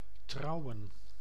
Ääntäminen
IPA: /ˈtrɑu̯.ə(n)/